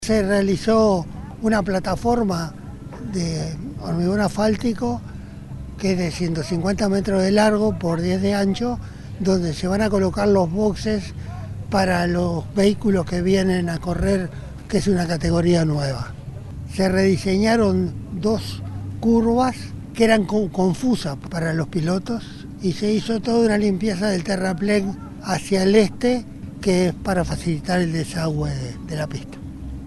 Se realizó la firma de convenio entre la Intendencia de Canelones y la Asociación Uruguaya de Volantes (AUVO) en el Autódromo Víctor Borrat Fabini de El Pinar en Ciudad de la Costa, cuyo objetivo es permitir el desarrollo de las obras de mantenimiento y ampliación del autódromo y las áreas circundantes.